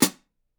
Closed Hats
West MetroHihat (3).wav